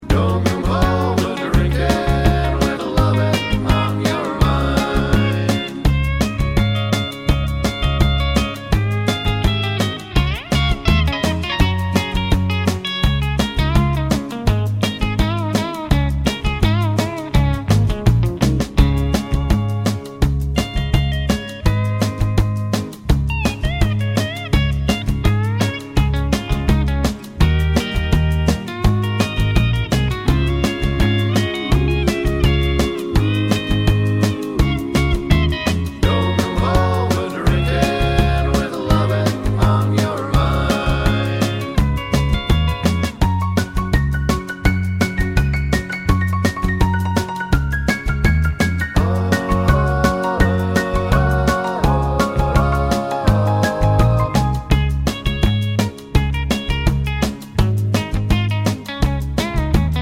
no Backing Vocals Country (Female) 2:07 Buy £1.50